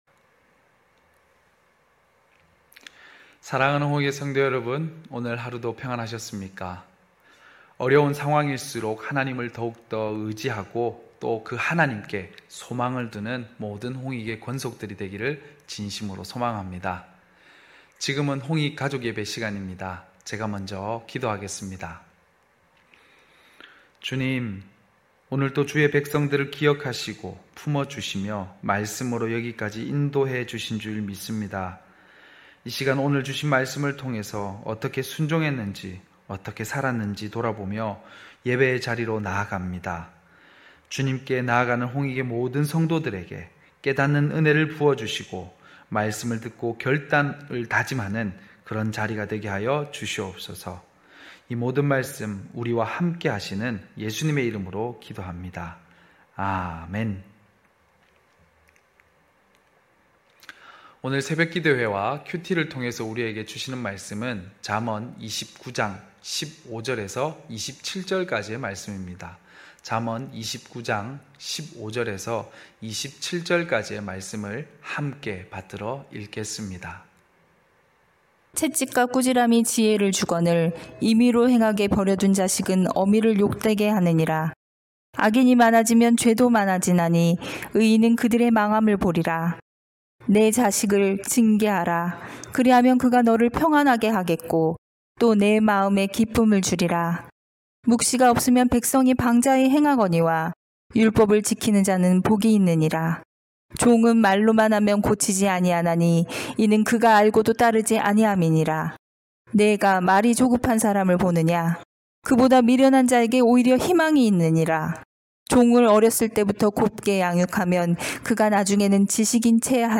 9시홍익가족예배(6월26일).mp3